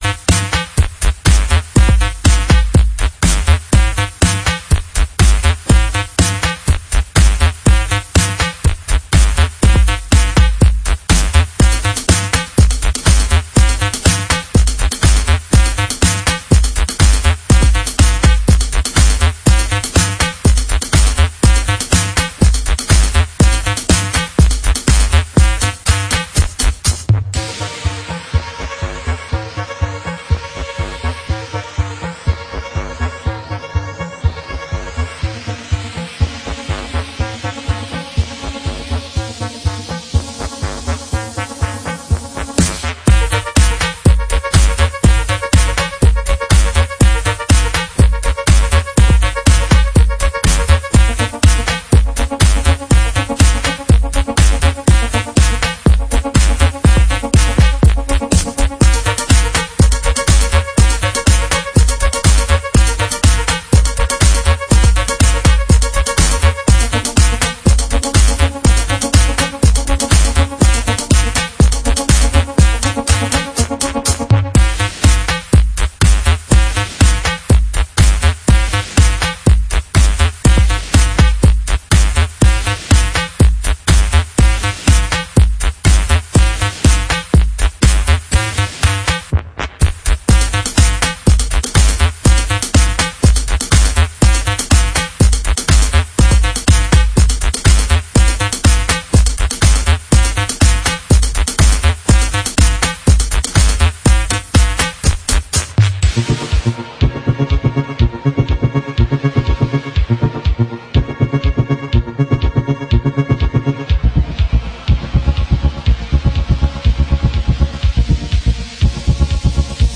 Disco House Soundtrack